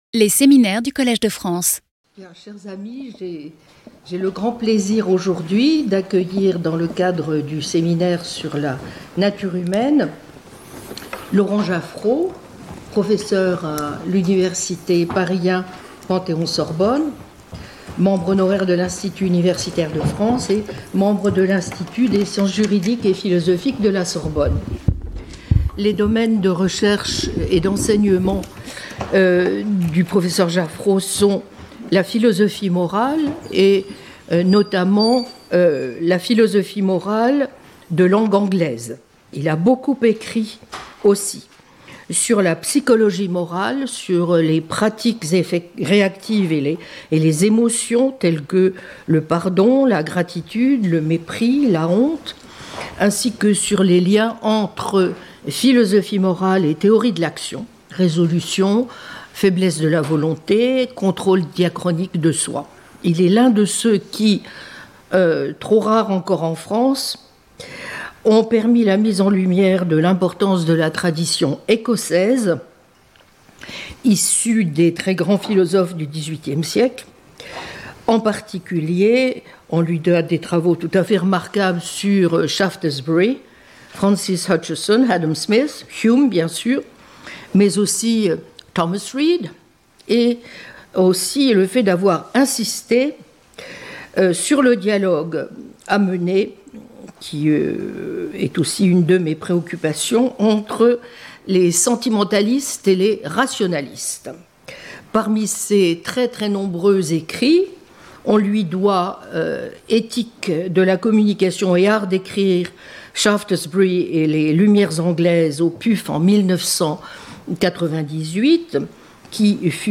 1 Séminaire